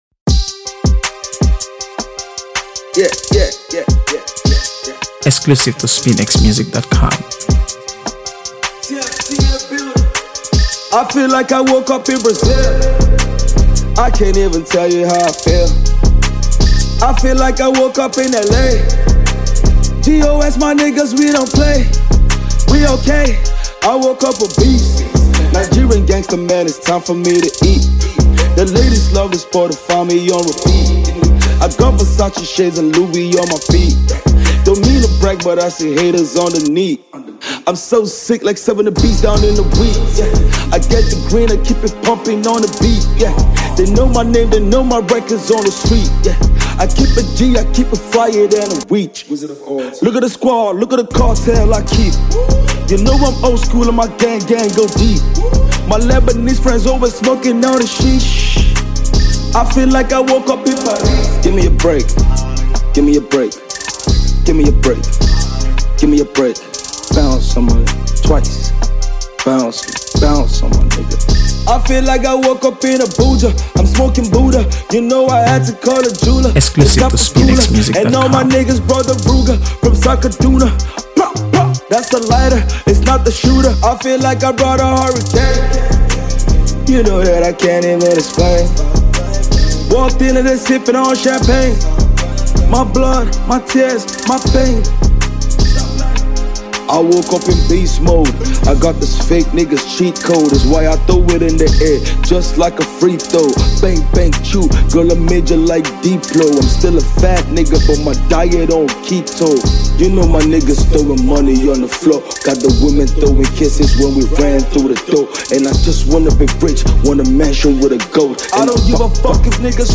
AfroBeats | AfroBeats songs
Nigeria’s famous rap artist and songwriter